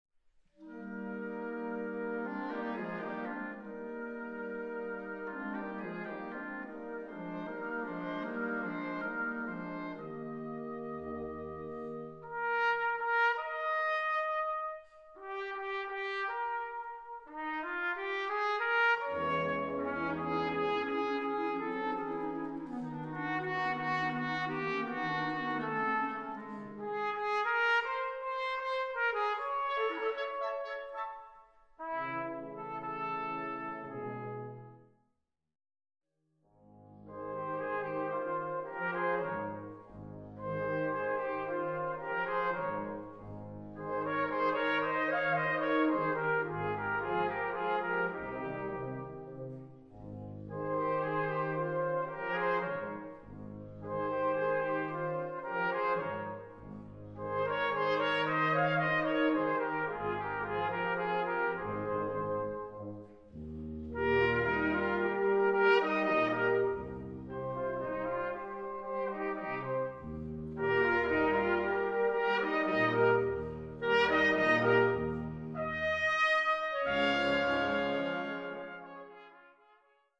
Solo für zwei Trompeten und Blasorchester
Besetzung: Blasorchester